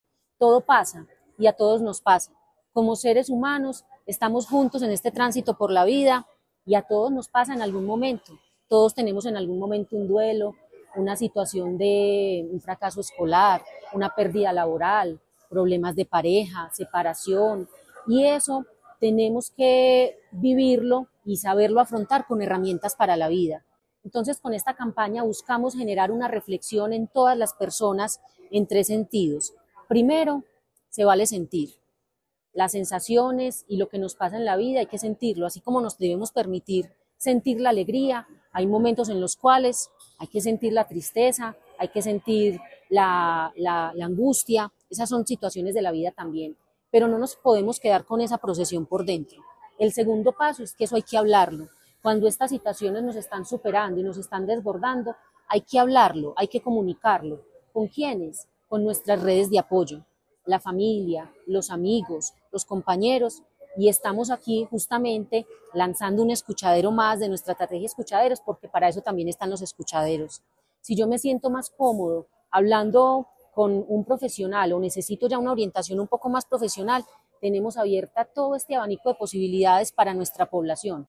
Palabras de Natalia López Delgado, secretaria de Salud